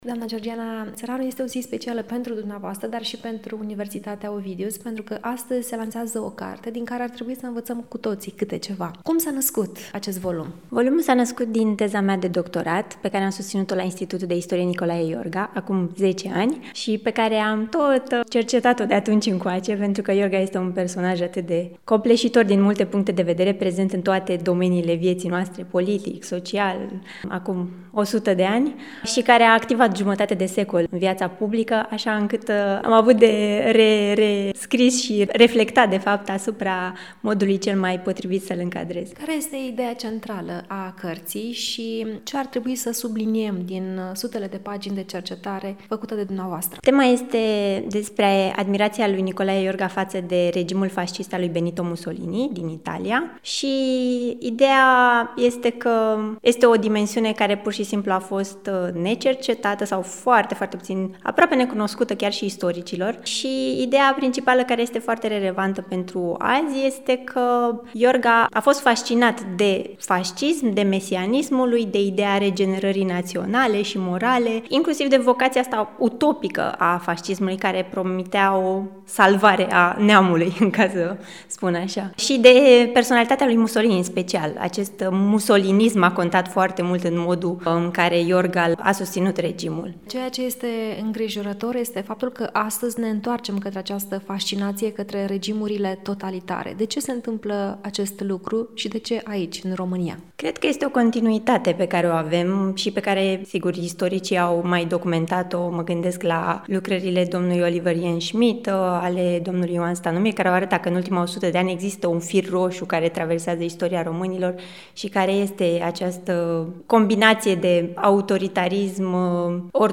În cadrul unui interviu acordat pentru Radio Constanța